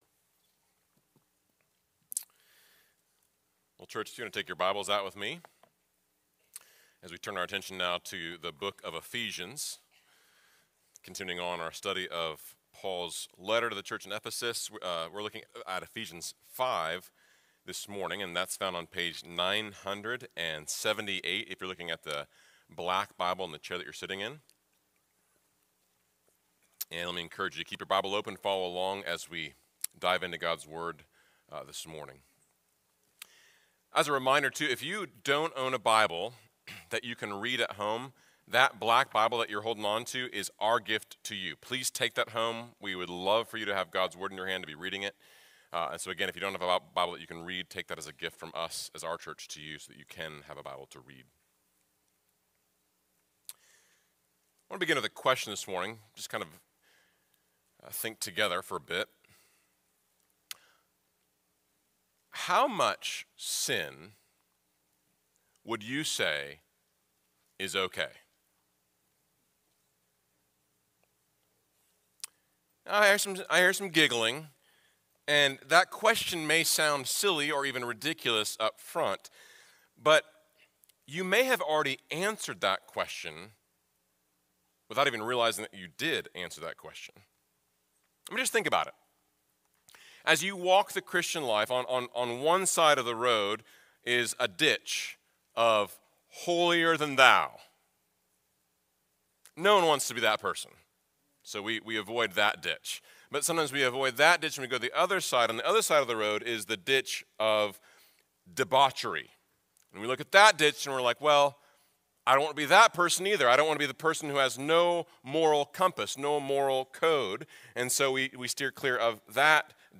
FBC Sermons